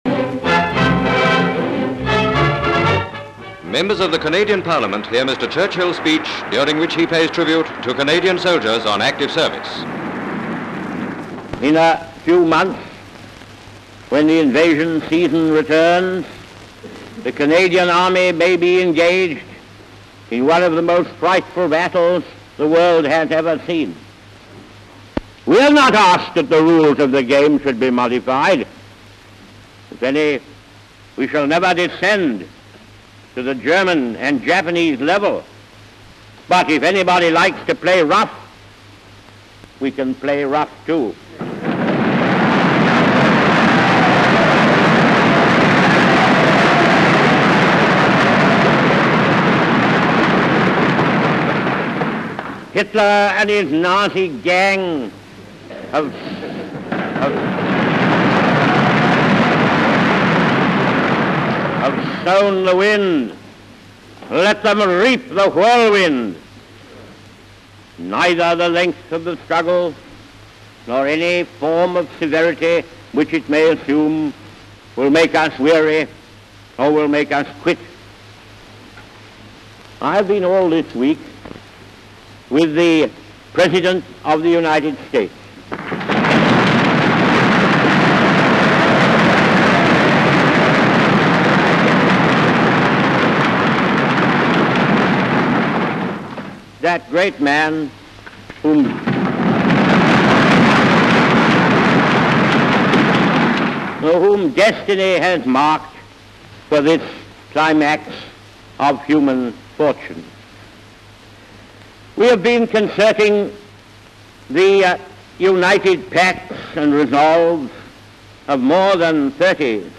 Sir Winston Churchill giving the "Some chicken...Some neck" speech in 1942.